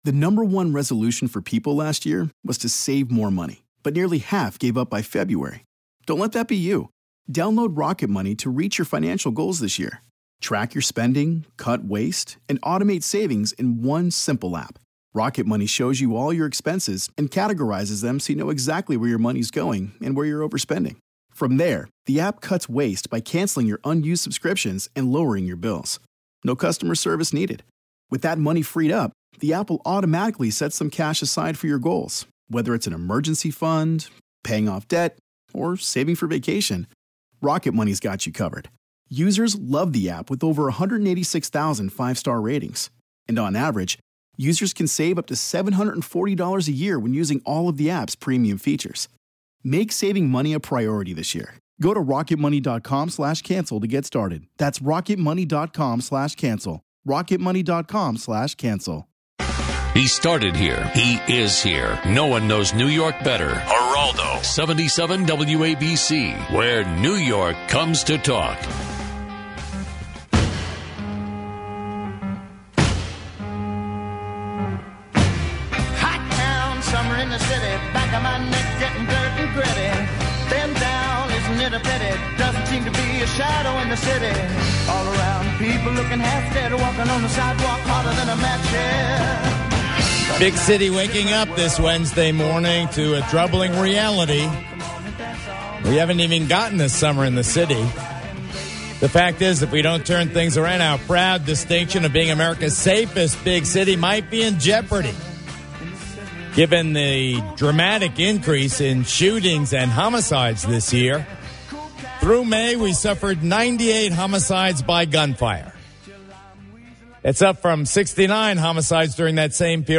Geraldo Rivera talks about issues New Yorkers care about.